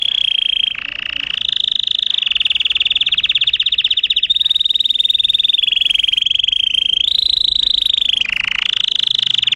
ringtone pajarito 8